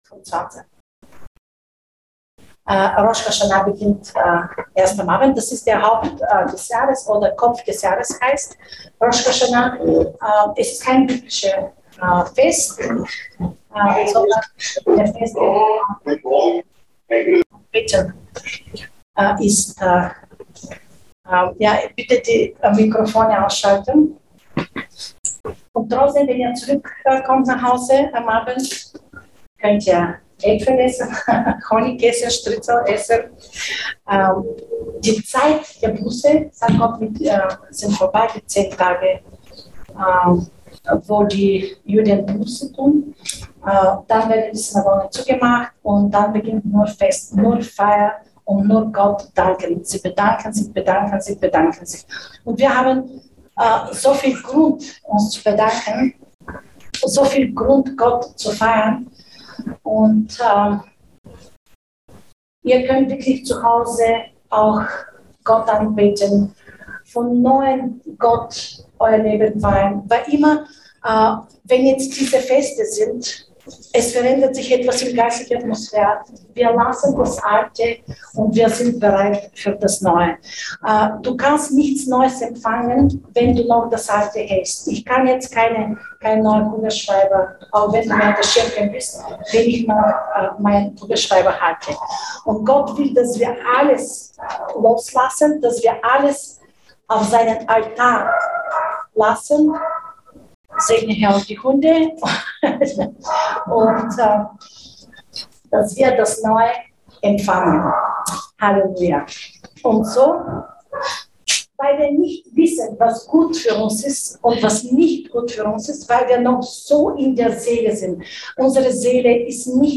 AT Website/1. Gottesdienstaufnahmen/2022/9.